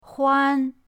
huan1.mp3